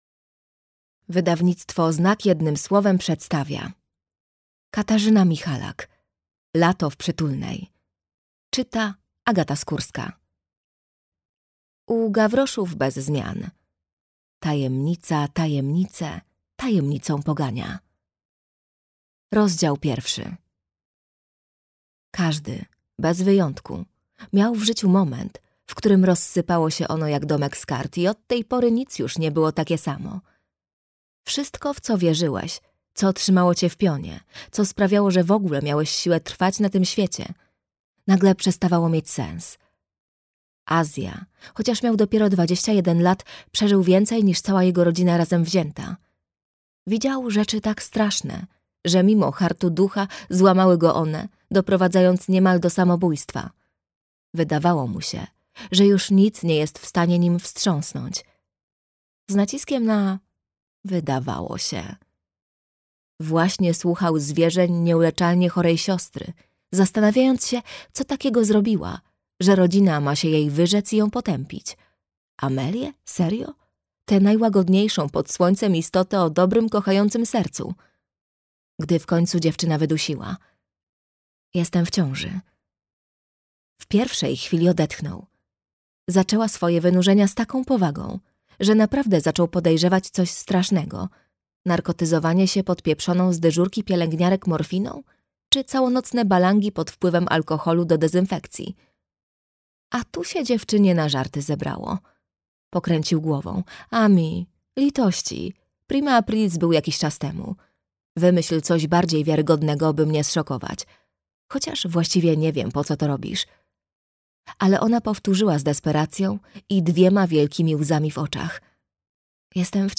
Lato w Przytulnej - Katarzyna Michalak - audiobook